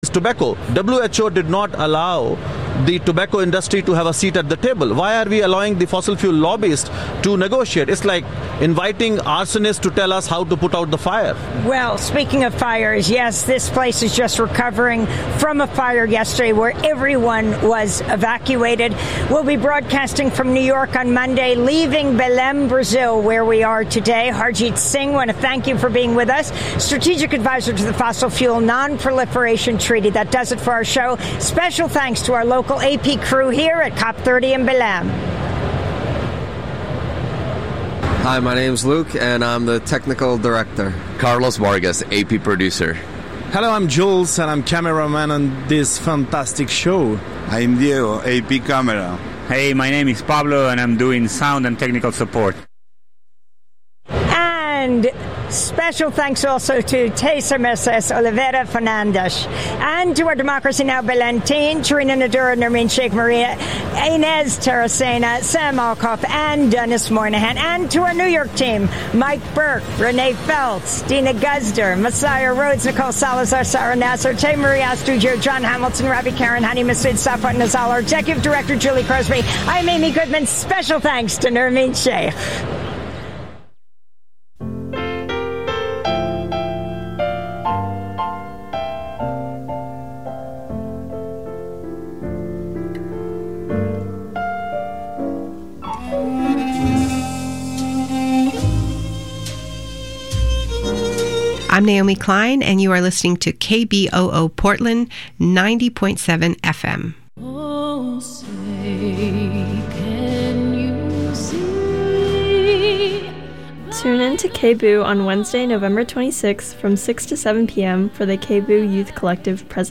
Non-corporate, community-powered, local, national and international news